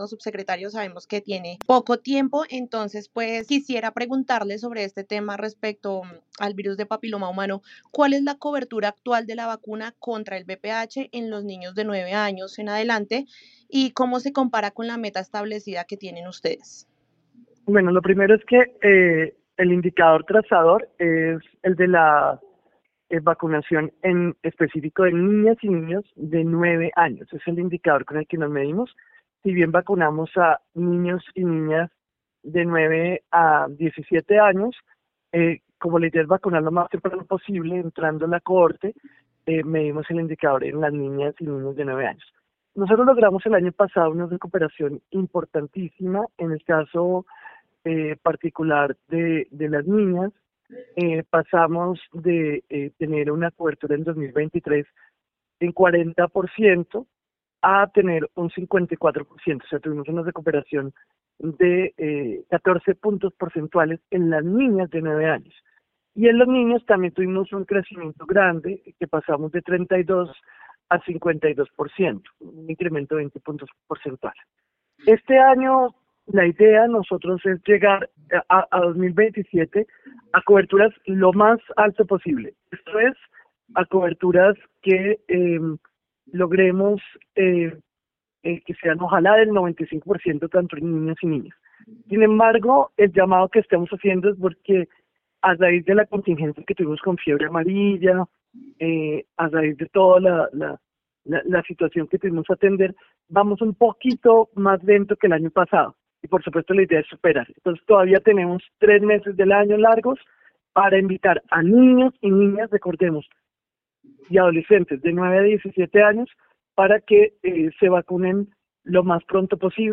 Caracol Radio habló con el Subsecretario de Salud Pública, Julián Fernández, quien afirmó que es baja la demanda de niños, niñas y adolescentes que se están vacunando contra el Virus del Papiloma Humano (VPH).